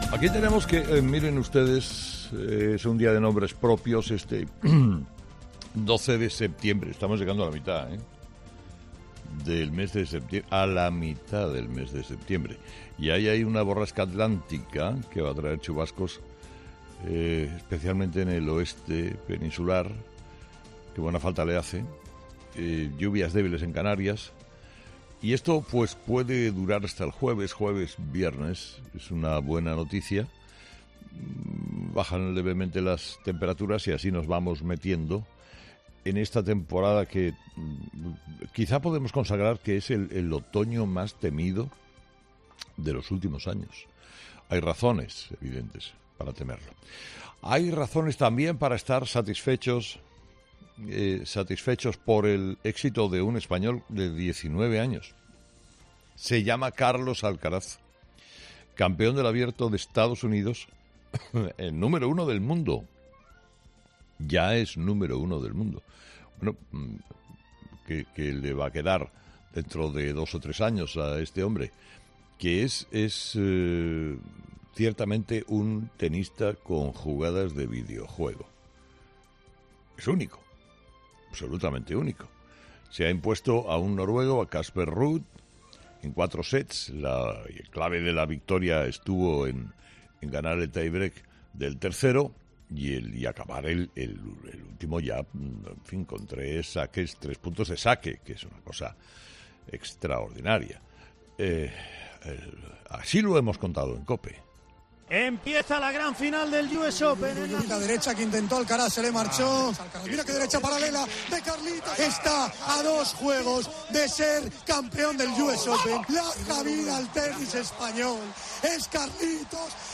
Carlos Herrera, director y presentador de 'Herrera en COPE', ha comenzado el programa de este lunes analizando las principales claves de la jornada, que pasan, entre otros asuntos, por la victoria de Alcaraz en el US Open y la nueva reunión de Díaz y Garzón con los distribuidores.